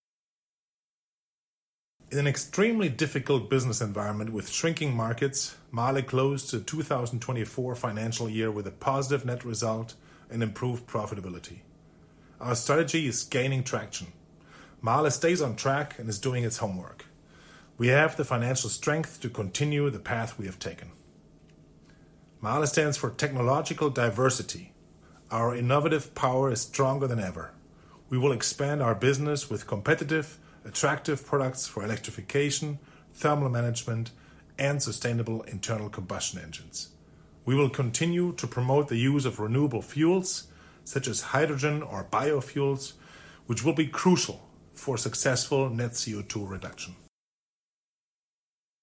Soundbite Business Strategy